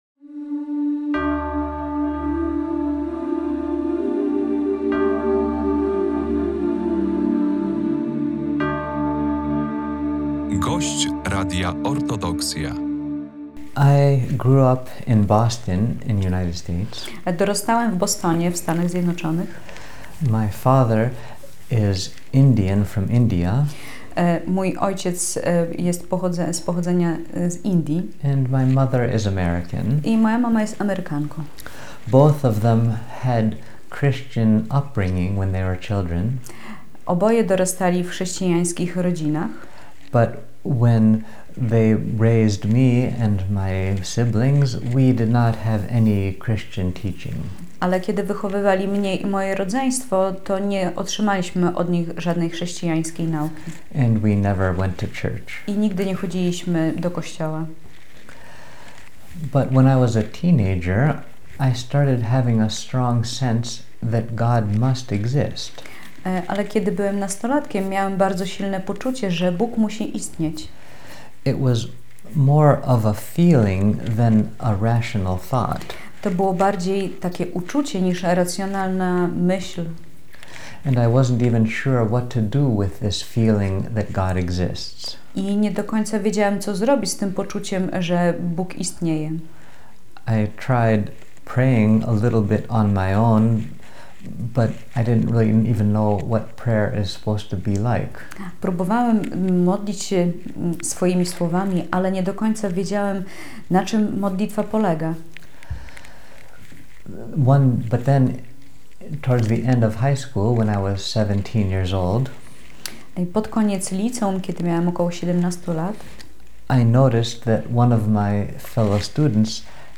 Materiał nagrany 7 listopada 2024 roku w monasterze w Zwierkach.